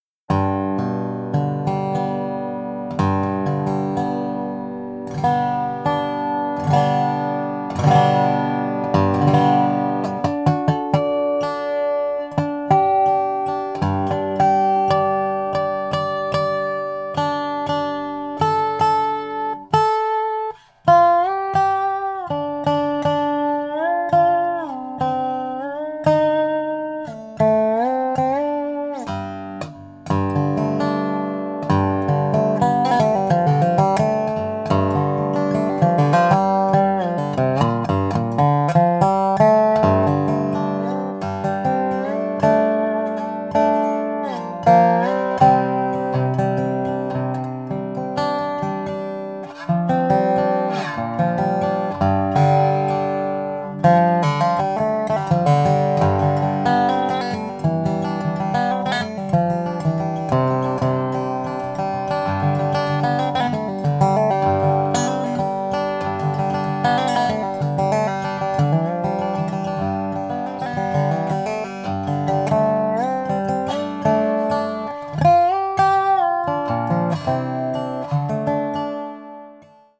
Tato rezofonická kytara je zvukově odlišná od ostatních. Díky netradiční kombinaci mahagonu a javoru je zvuk nástroje temnější.
Výšky jsou kulatější a přitom stále průrazné, basy jsou konkrétní a celkový zvuk je velmi vyvážený.
SoundSample_Mistr_MahoganyMaple.mp3